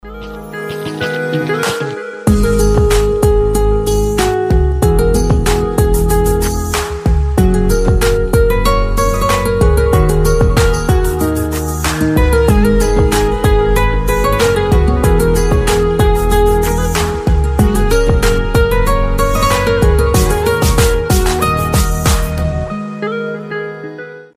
• Качество: 320, Stereo
гитара
спокойные
без слов
красивая мелодия
инструментальные
индийские
Красивая инструментальная музыка